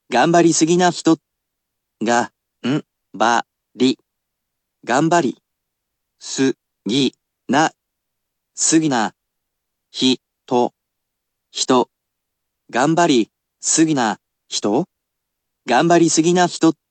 Today, I’ll tell you how to pronounce part of the title of today’s video~!
頑張りすぎな人【がんばりすぎなひと】※